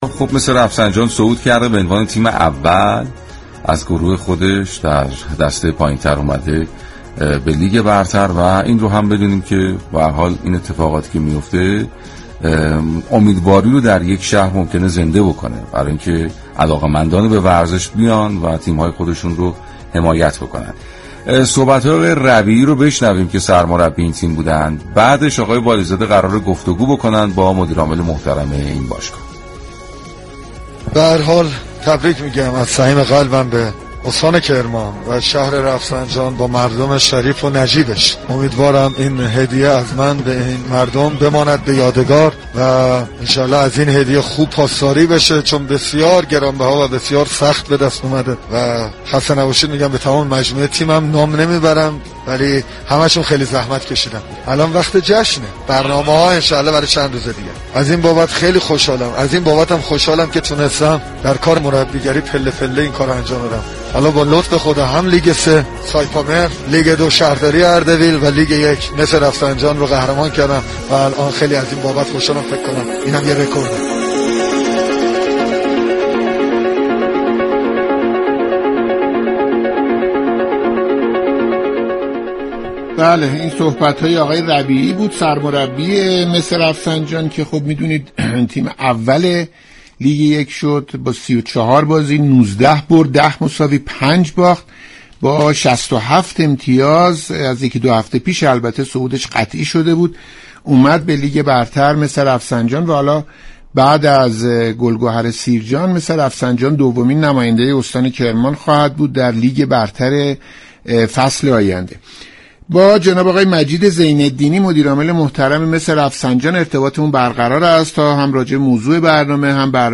شما می توانید از طریق فایل صوتی پیوست شنونده این گفتگو باشید.